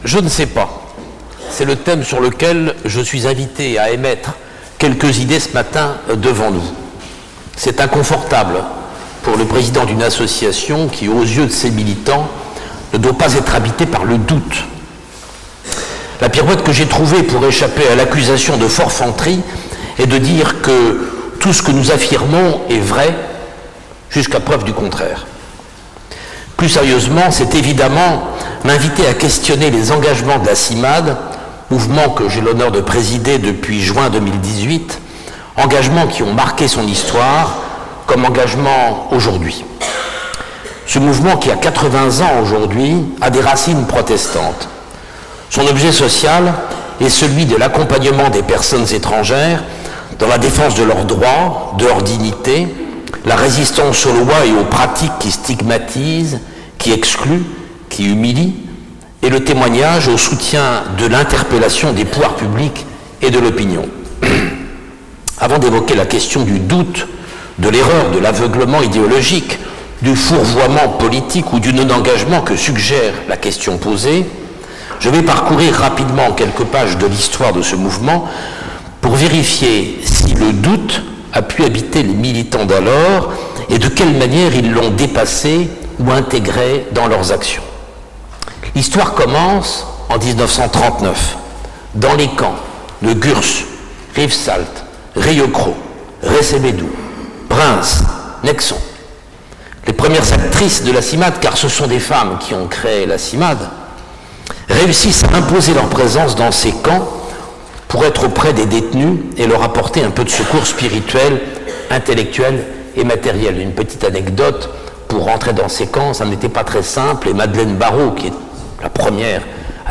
Culte-conférence du 26 janvier 2020
Version courte (conférence sans culte)